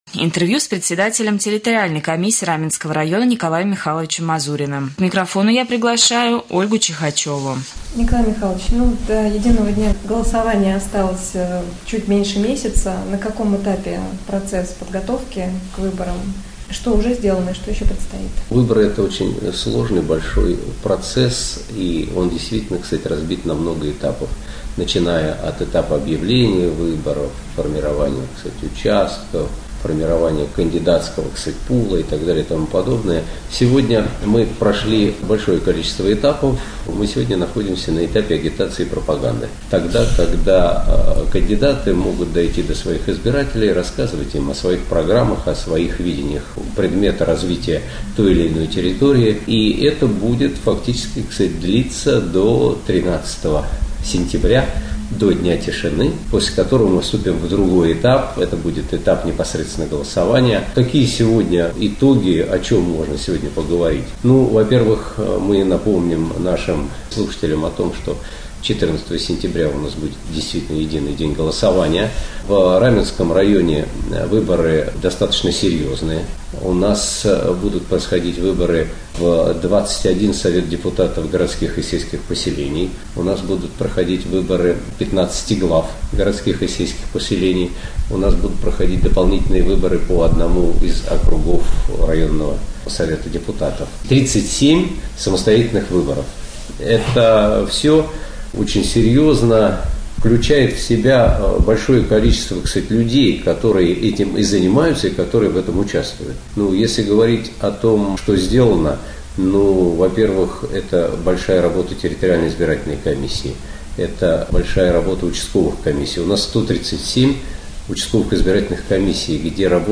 2.Рубрика «Актуальное интервью».